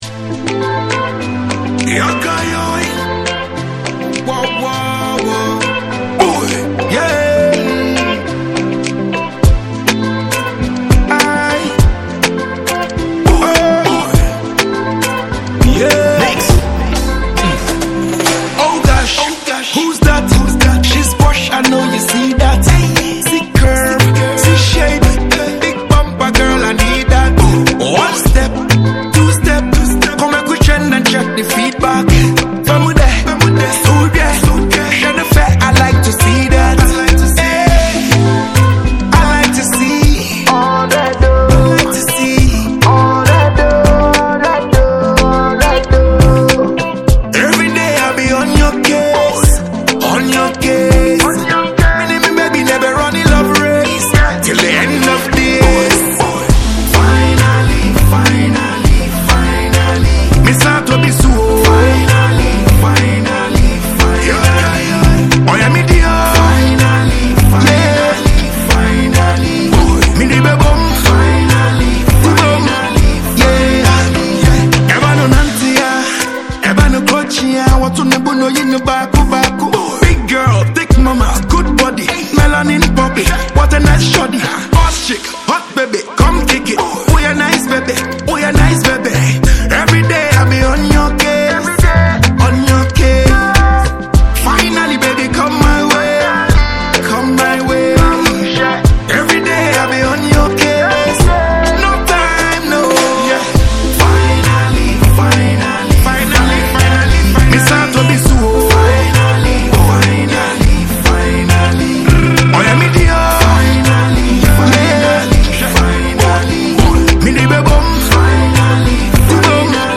• Genre: Reggae / Dancehall / Afrobeat